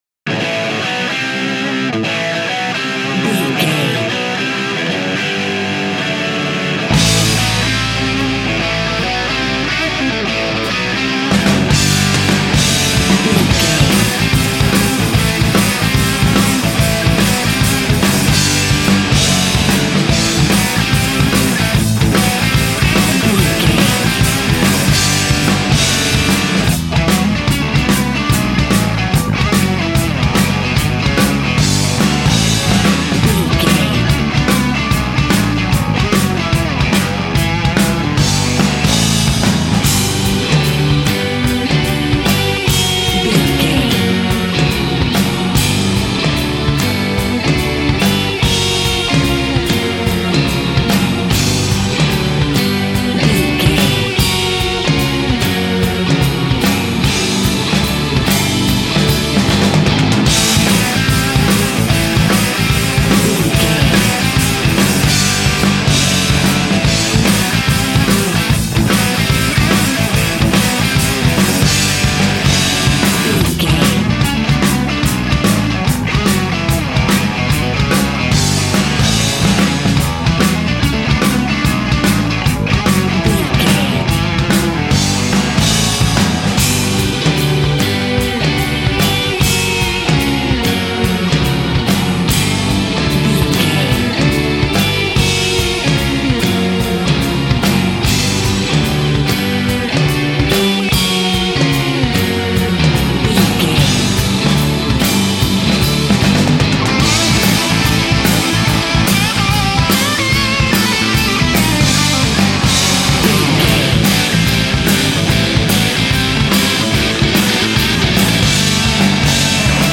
Aeolian/Minor
Fast
drums
electric guitar
Sports Rock
hard rock
lead guitar
bass
aggressive
energetic
intense
nu metal
alternative metal